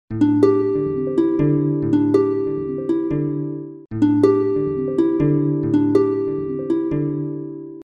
спокойные
без слов
Мелодичный и спокойный будильник